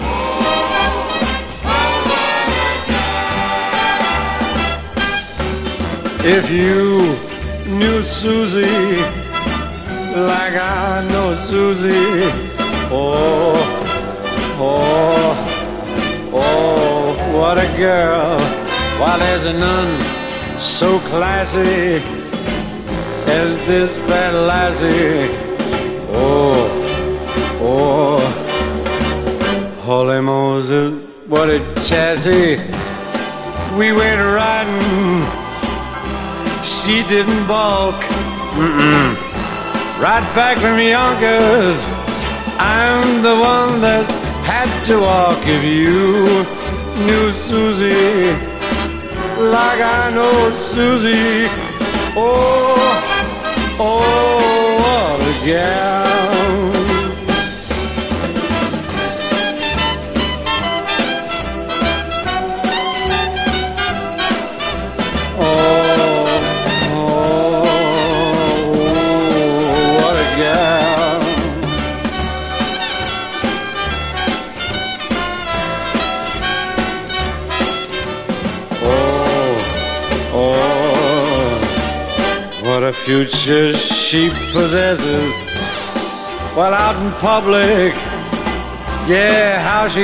60年代後半のフラワーな空気感を詰め込んだカヴァー・アルバム！